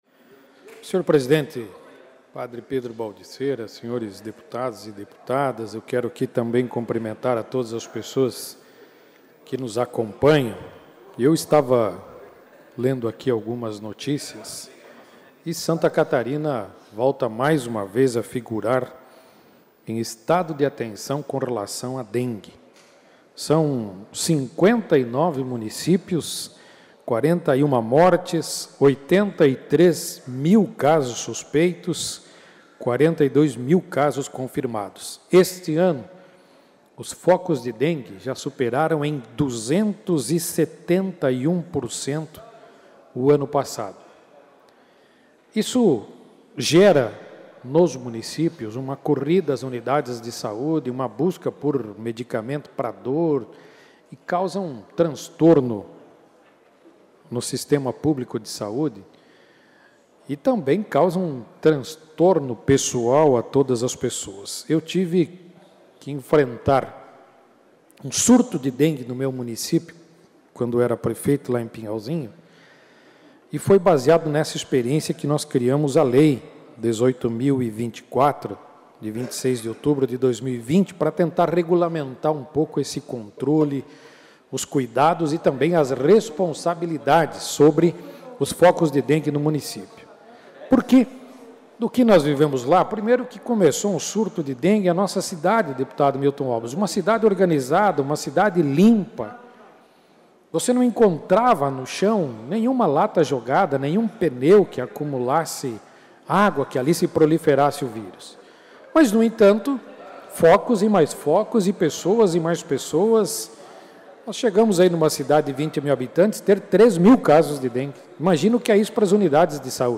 Confira os pronunciamentos dos deputados na sessão ordinária desta terça-feira (24):